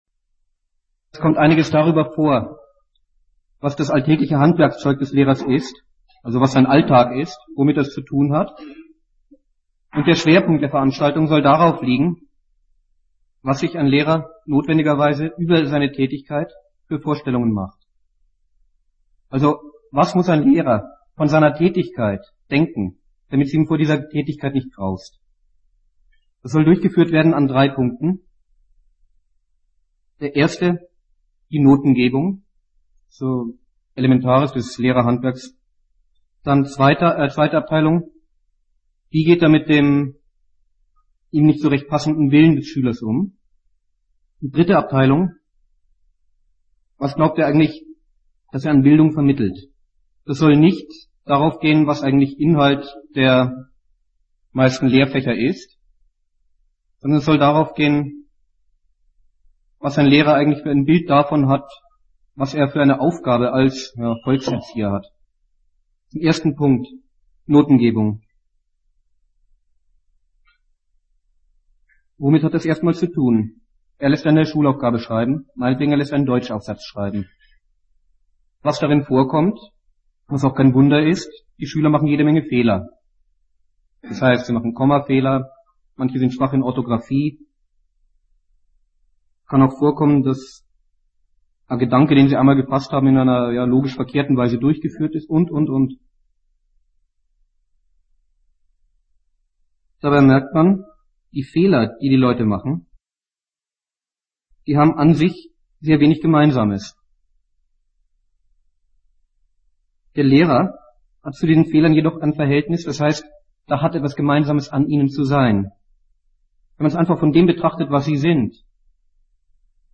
Gliederung des Vortrags: Teil 1: Notengebung Teil 2: Motivation und Disziplinierung Teil 3: Oberste Erziehungsziele Die Redebeiträge aus dem Auditorium sind zum Teil unverständlich und wurden entfernt